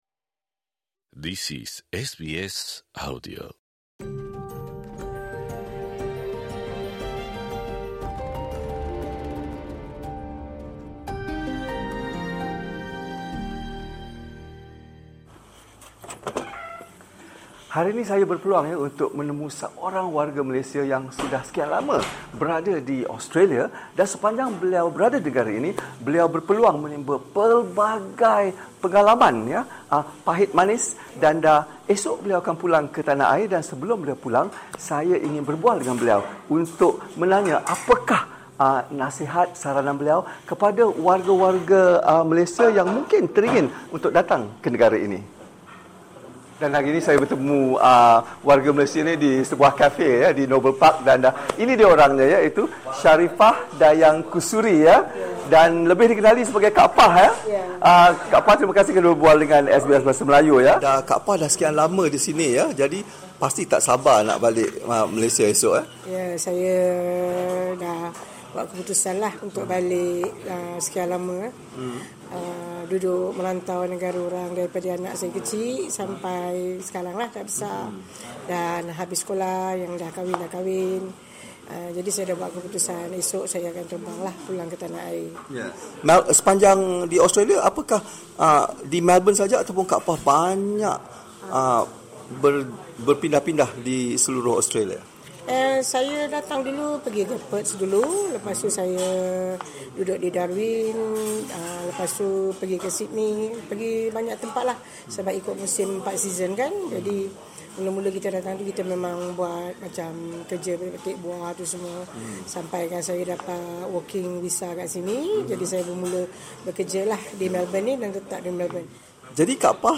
temubual